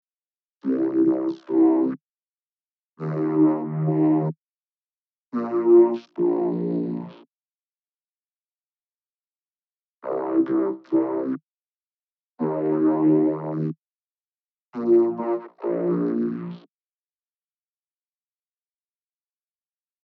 ベースパートも基本的にはこれまでの設定に近い設定にしてますが、これまでのパートよりロボットっぽさを出したかったので、Number of Bandsを16バンドに変更（先のパートでは32バンドで作成）したり、不要なディレイリバーブは無しでその他音質の調整を行いました。
ベースパートはこちら
Vocal_bass_solo.mp3